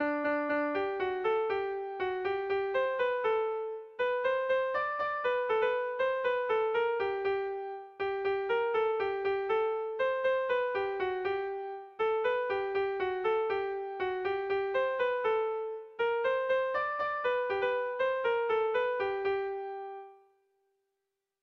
Hamarreko txikia (hg) / Bost puntuko txikia (ip)
ABDEB